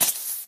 creeper.mp3